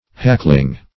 Hackling - definition of Hackling - synonyms, pronunciation, spelling from Free Dictionary
p. pr. & vb. n. Hackling (h[a^]k"kl[i^]ng).]